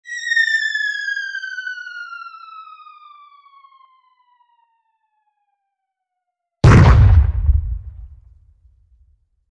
Cartoon Falling 2 Sound Effect Free Download
Cartoon Falling 2